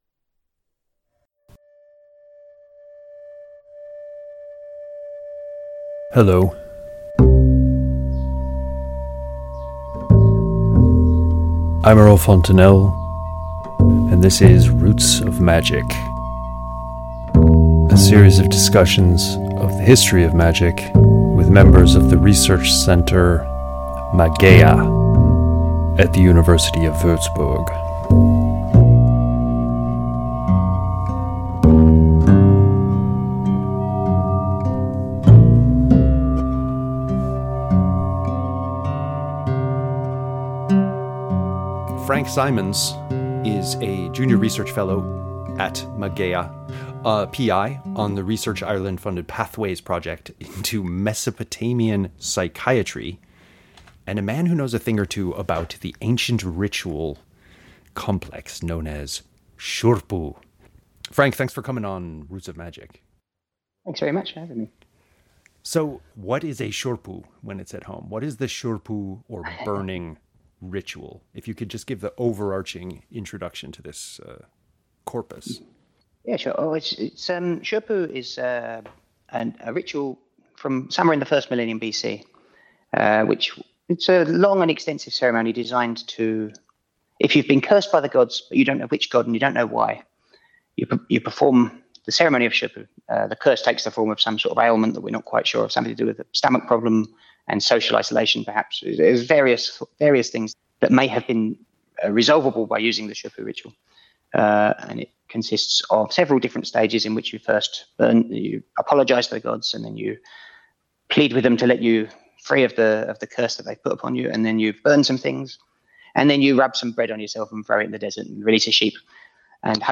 Roots of Magic Interview 6